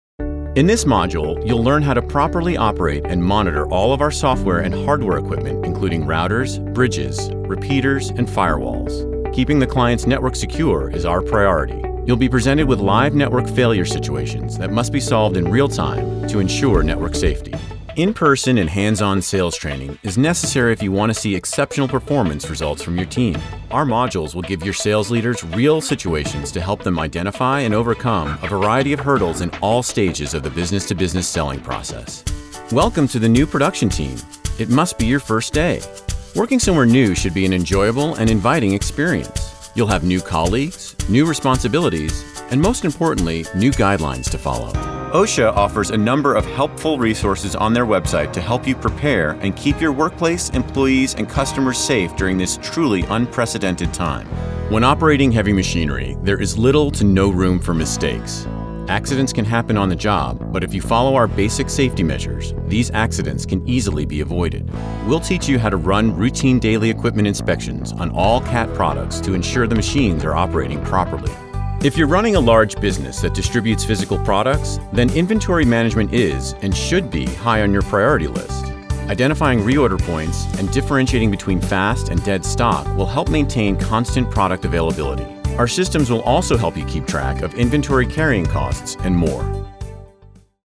E-Learning